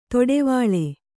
♪ toḍevāḷe